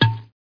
1 channel
bottle03.mp3